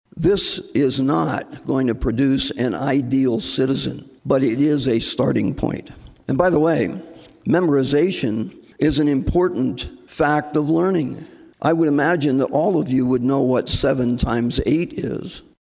REPRESENTATIVE BOB HENDERSON OF SIOUX CITY LED HOUSE DEBATE BACK IN APRIL:.